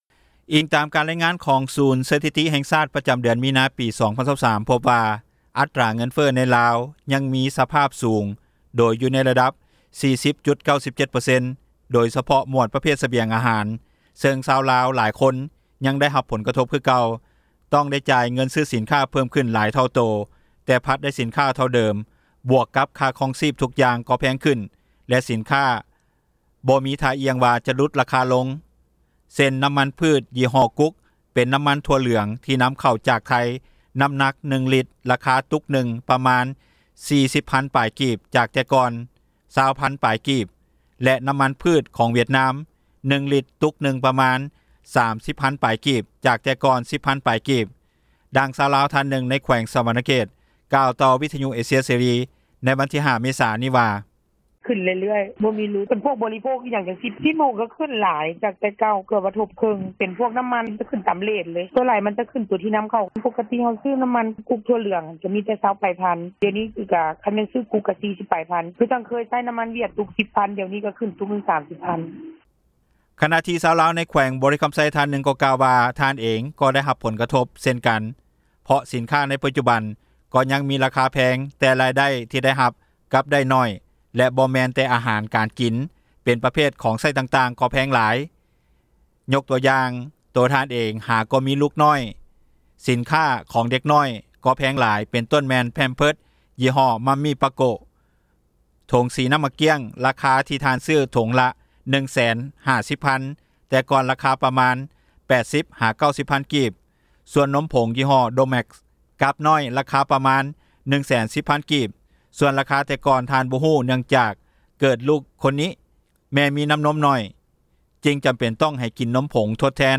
ດັ່ງຊາວລາວ ທ່ານນຶ່ງ ໃນແຂວງສວັນນະເຂດ ກ່າວຕໍ່ວິທຍຸເອເຊັຽເສຣີ ໃນວັນທີ 05 ເມສາ ນີ້ວ່າ: